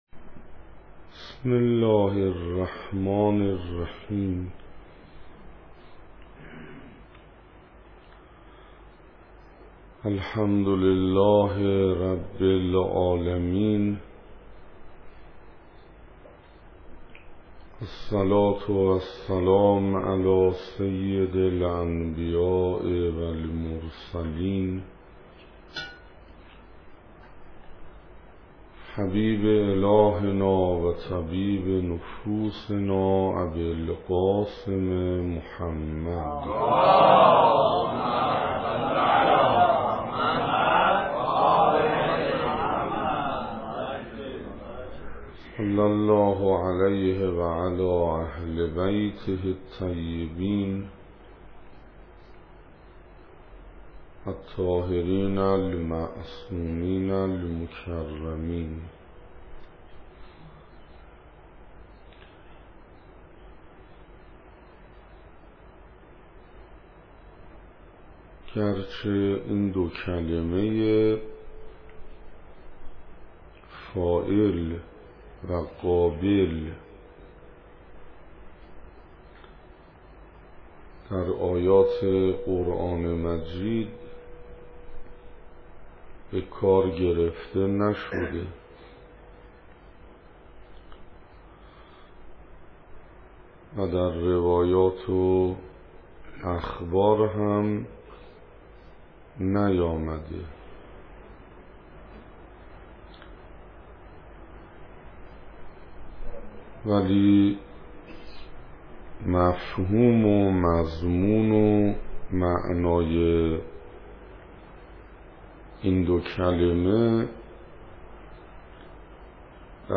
سخنراني دوم
حسينيه سماواتيان - سال 1384